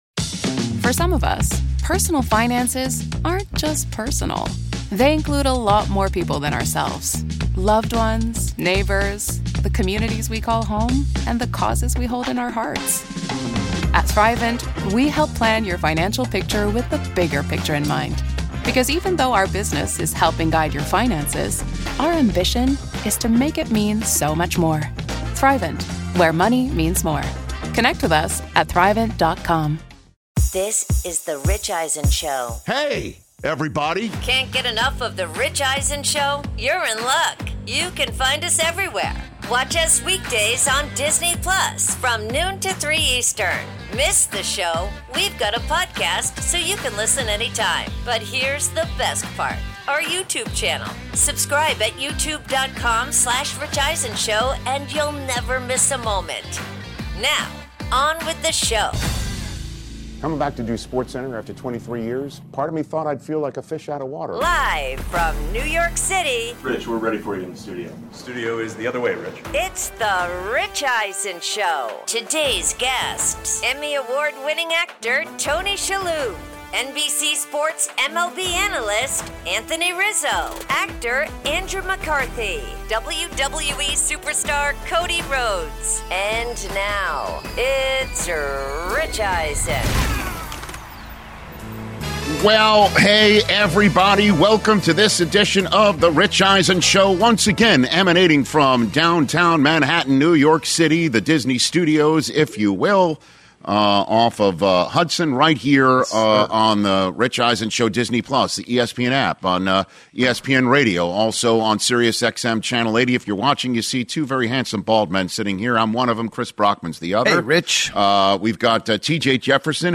Hour 1: CJ Stroud Extension Debate, AJ Brown Latest, plus Actor Tony Shalhoub In-Studio